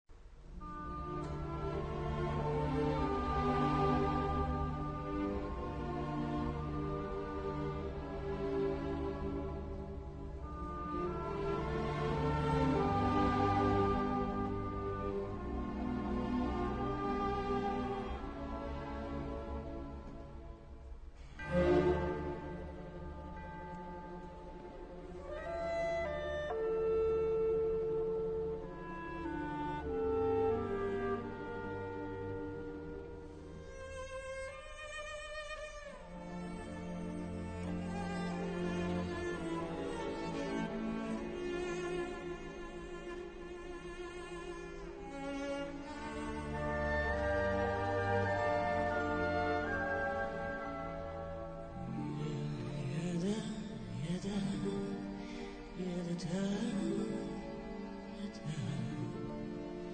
key: D-minor